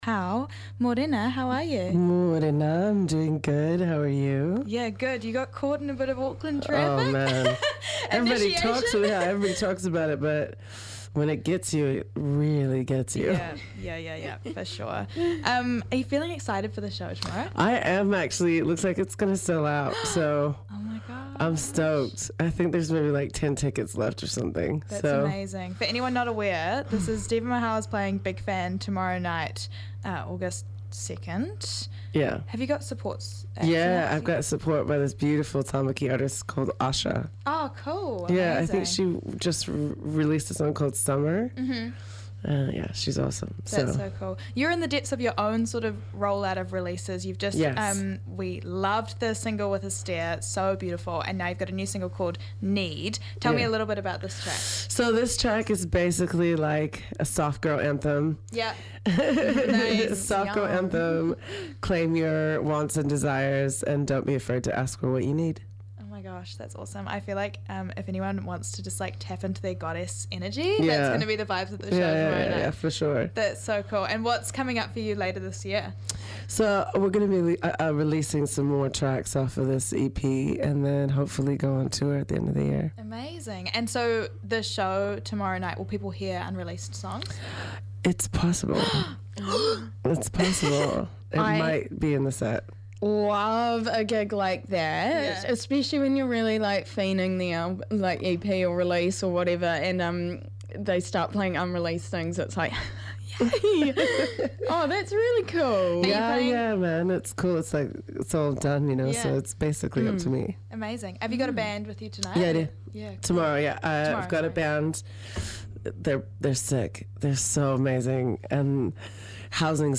Guest Interview w
is up in the studio for a chat about her latest single 'Need' and her show at Big Fan tomorrow night!